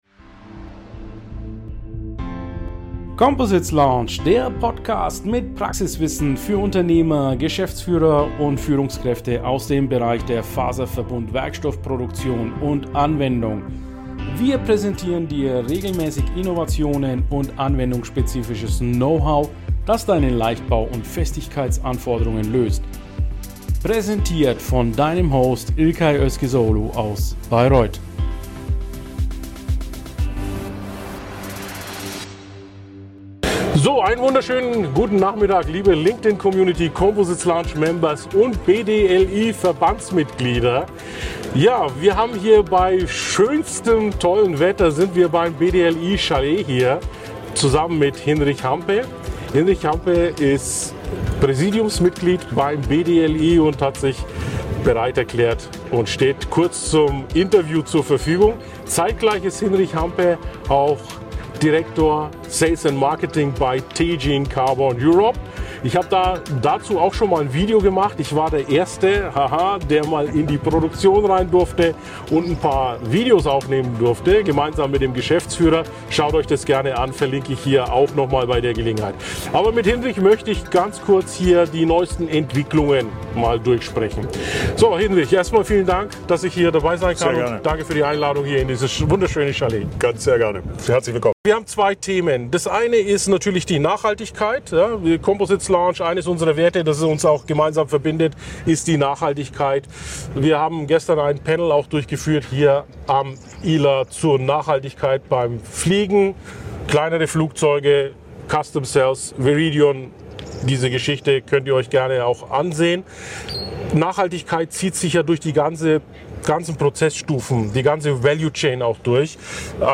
#128 Interview